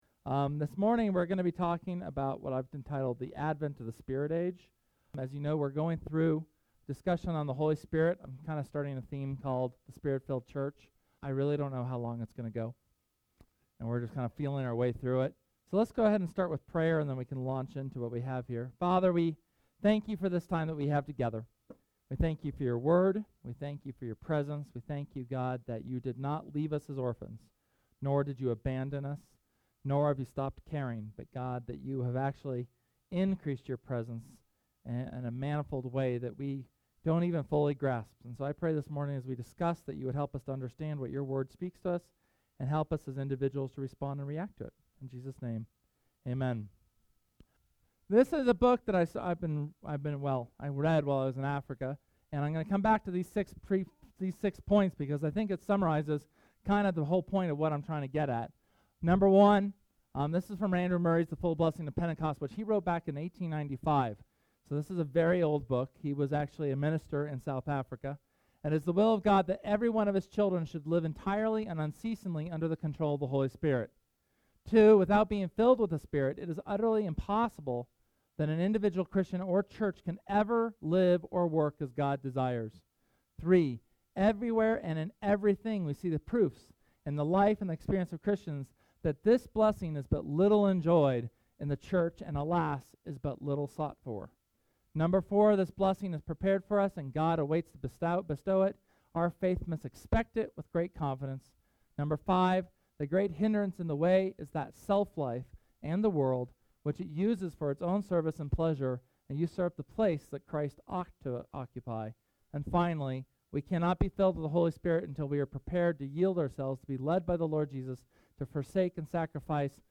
SERMON: The Advent of the Spirit Age (HS #3)